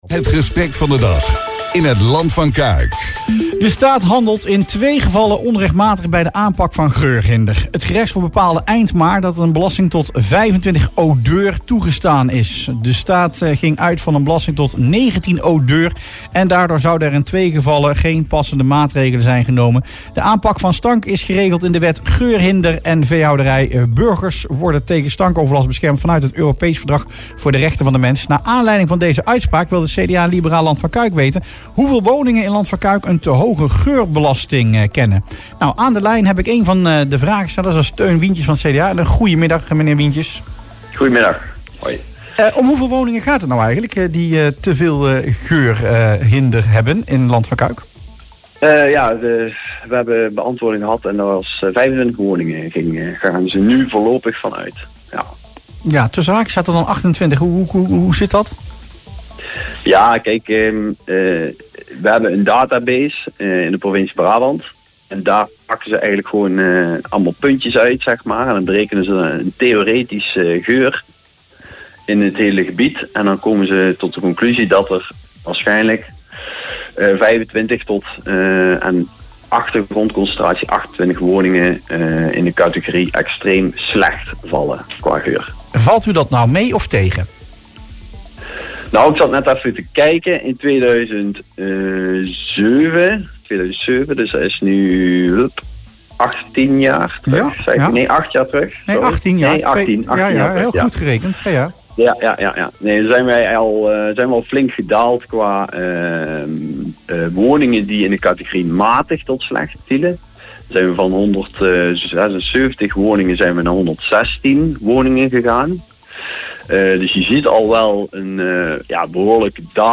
CDA-raadslid Teun Wientjes in Rustplaats Lokkant